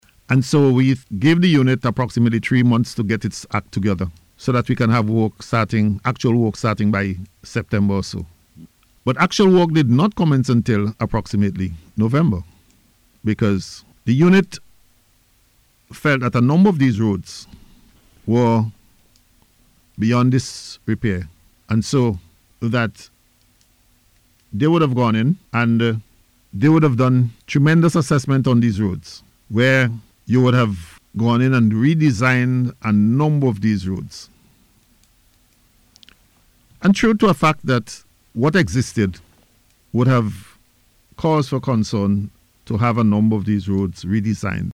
Speaking on NBC’s Face to Face Programme this morning, Minister Daniel said in most instances the government will provide the funding for the programme, from either government resources or partners.